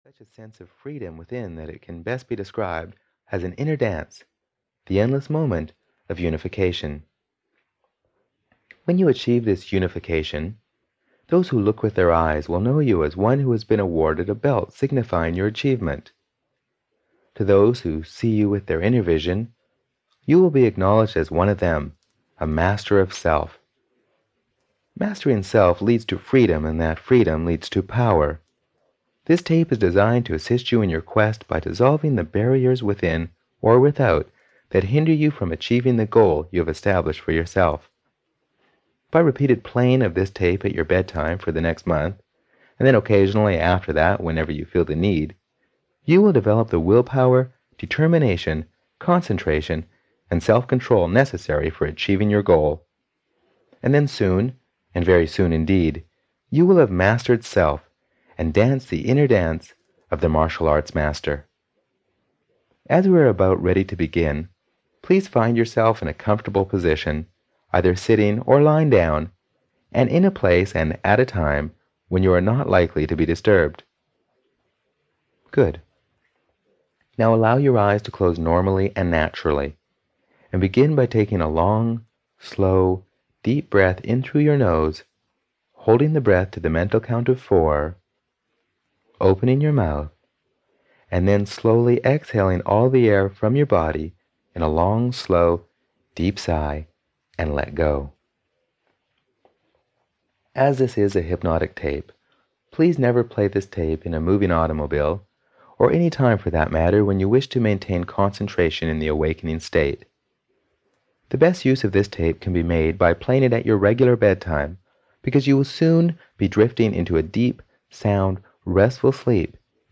Master Martial Arts (EN) audiokniha
Ukázka z knihy